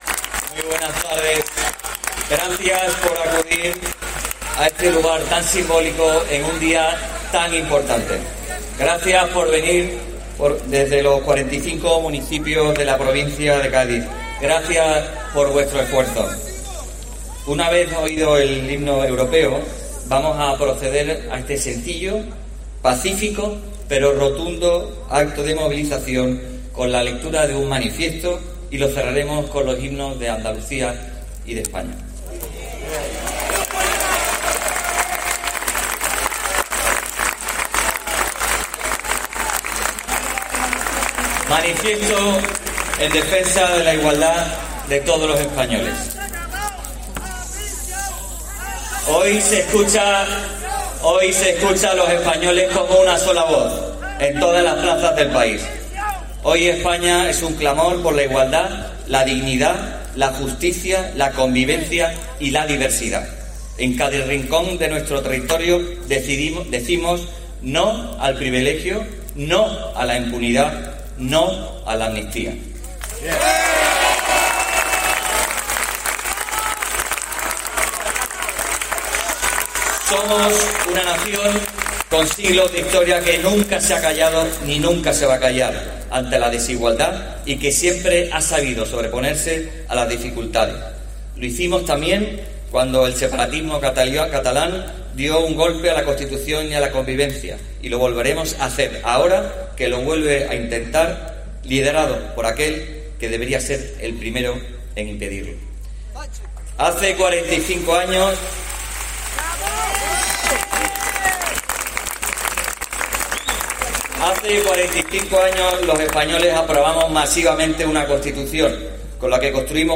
El presidente del PP de Cádiz, Bruno García, da lectura al manifiesto por la igualdad